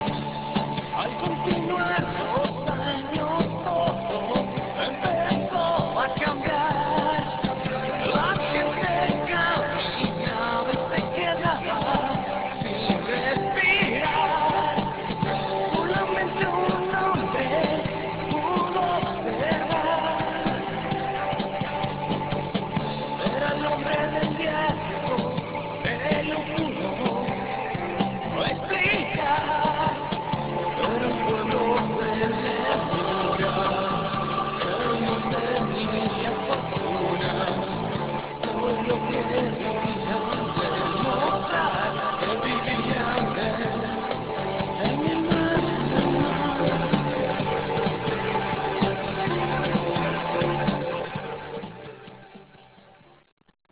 Instrumental
ellos logran salirse con la suya y tocar su New Wave.